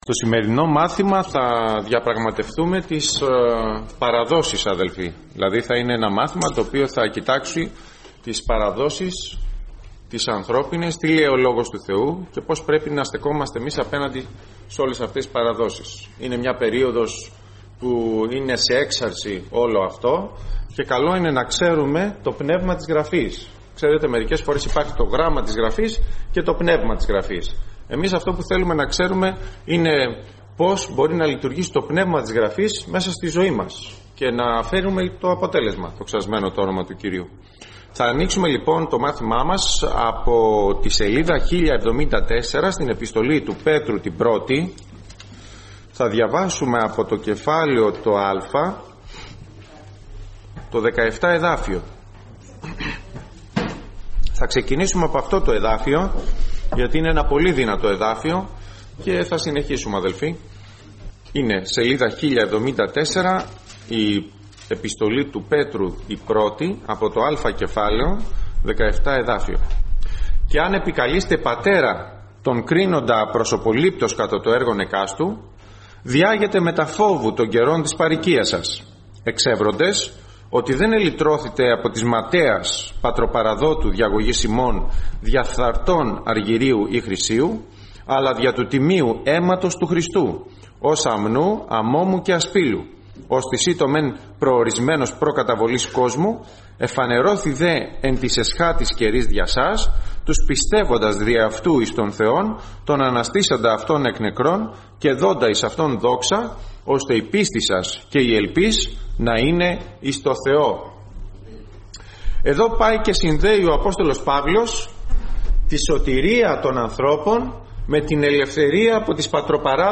Series: Μαθήματα